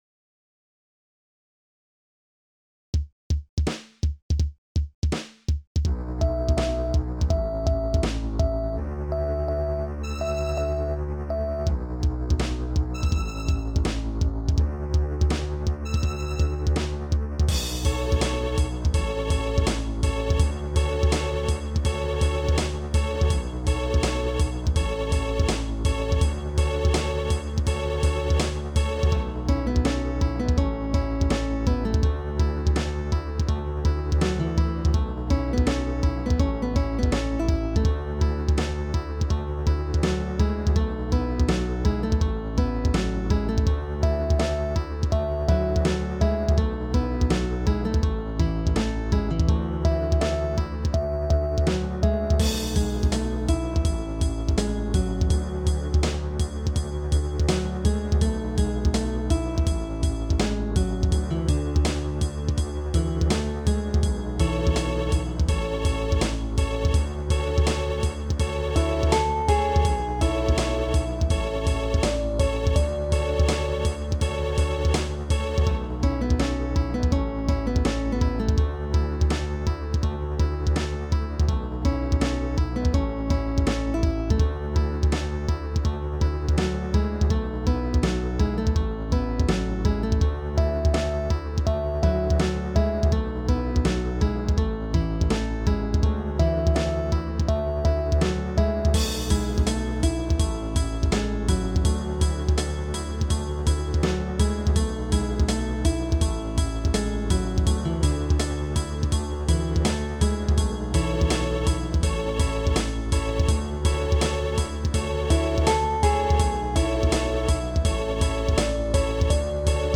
A MIDI composition